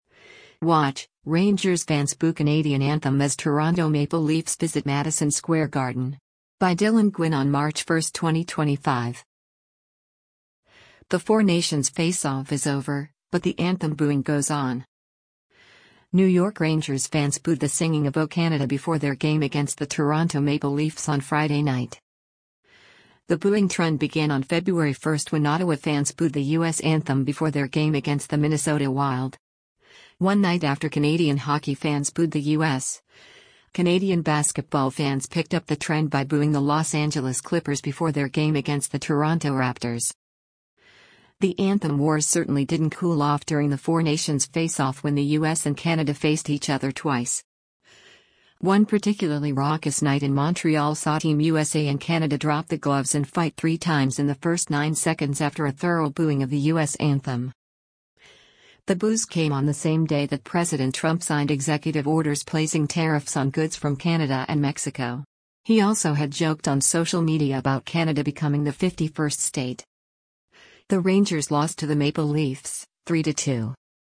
WATCH: Rangers Fans Boo Canadian Anthem as Toronto Maple Leafs Visit Madison Square Garden
New York Rangers fans booed the singing of O Canada before their game against the Toronto Maple Leafs on Friday night.